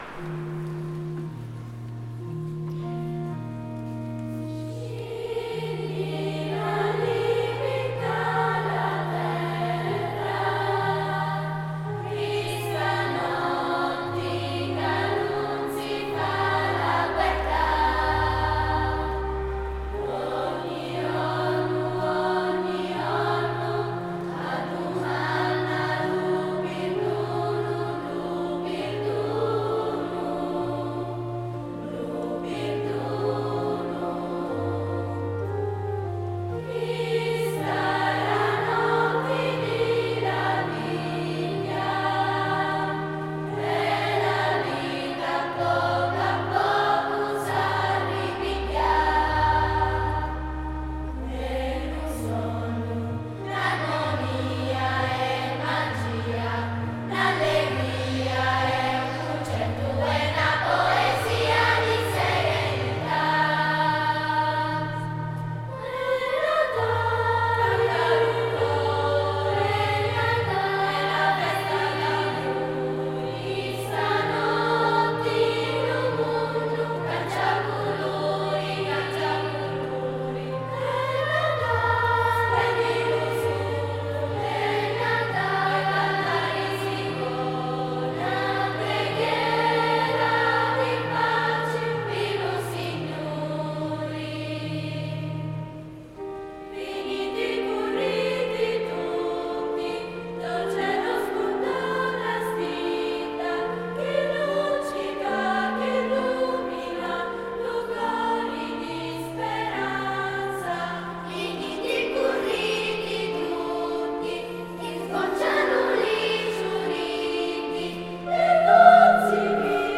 Gallery >> Audio >> Audio2017 >> Rassegna Corali Diocesane >> 13b-RassCorali 26Nov2017 PueriBalestrate2